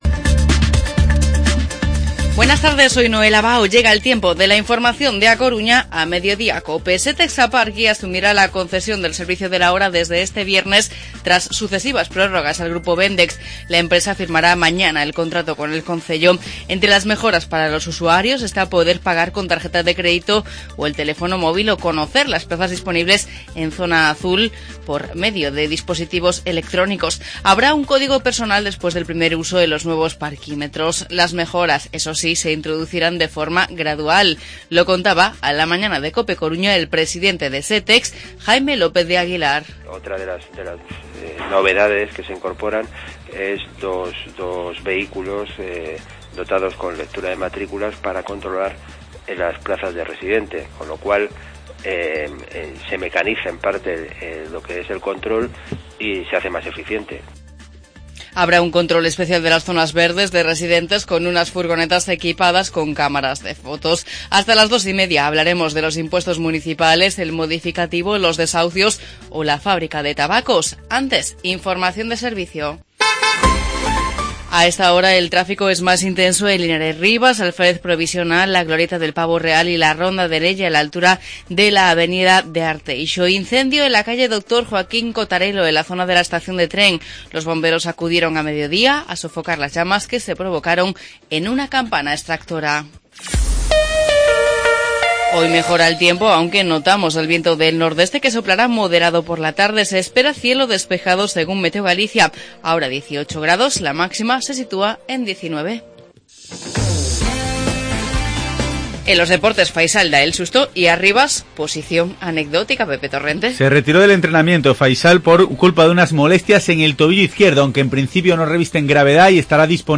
Informativo Mediodía COPE Coruña martes, 13 de octubre de 2015